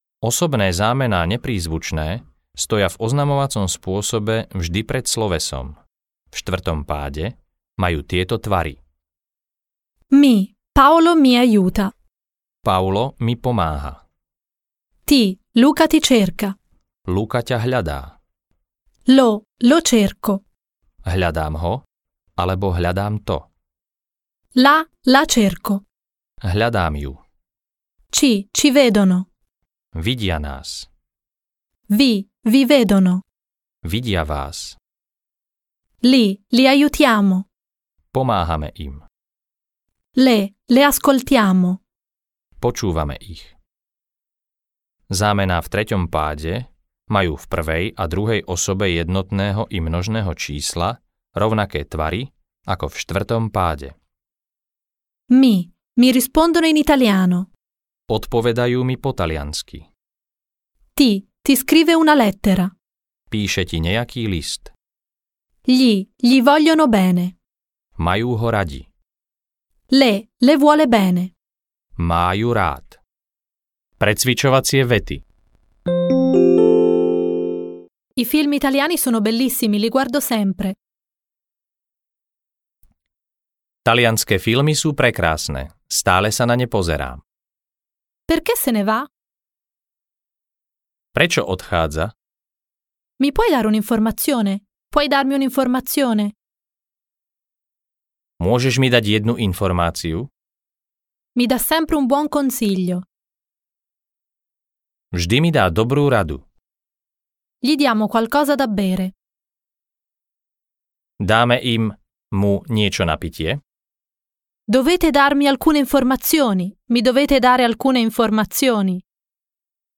Taliančina do ucha audiokniha
Ukázka z knihy